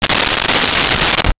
Close Shower Curtain 01
Close Shower Curtain 01.wav